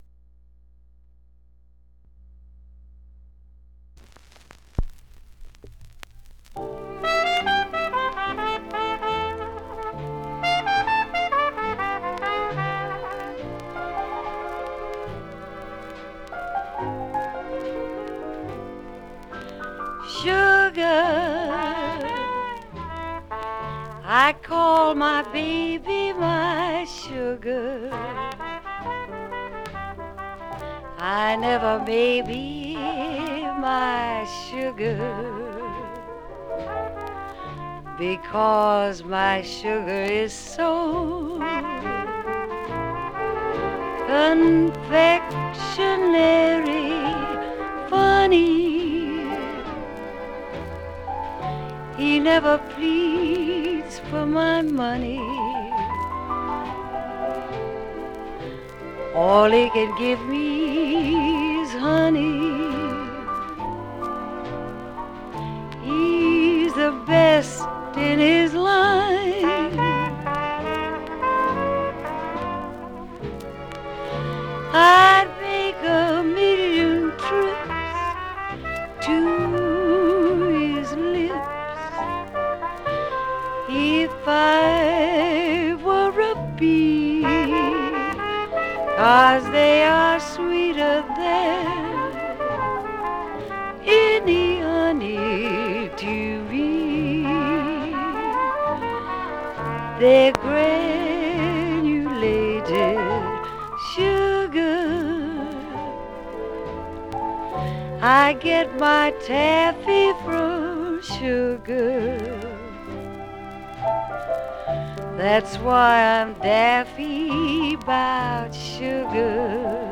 Some surface noise/wear
Mono
Jazz